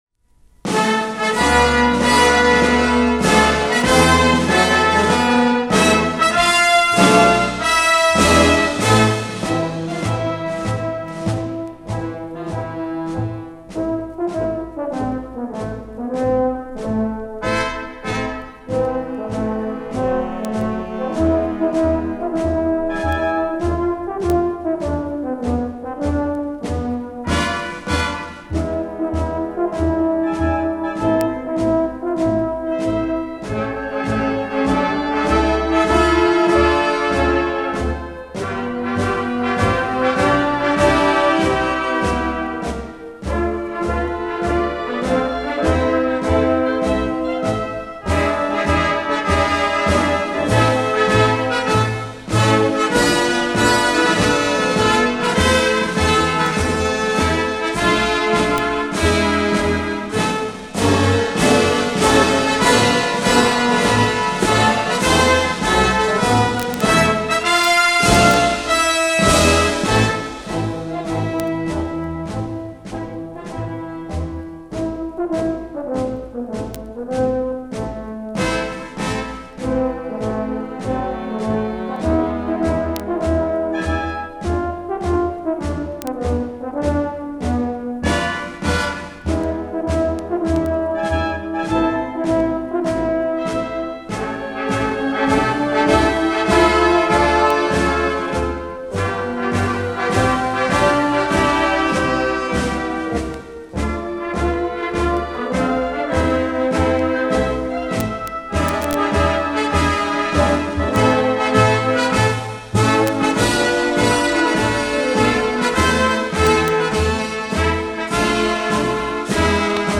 Описание: Инструментальная версия.